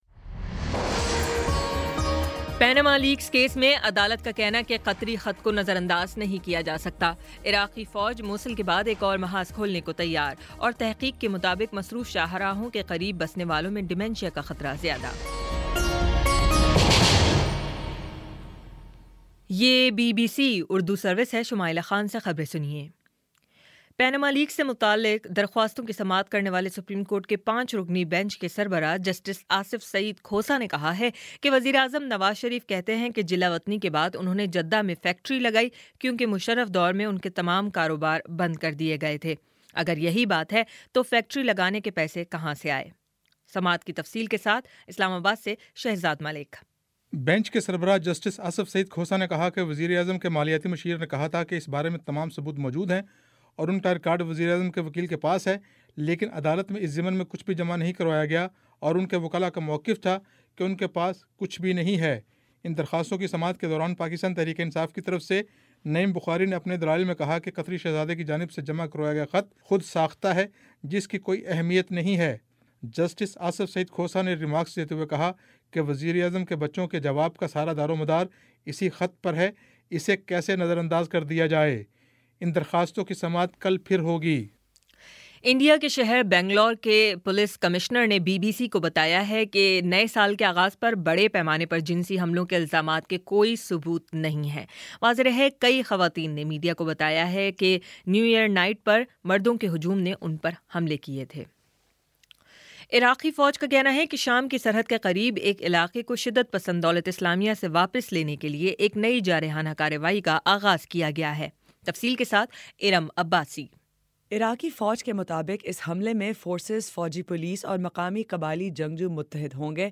جنوری 05 : شام پانچ بجے کا نیوز بُلیٹن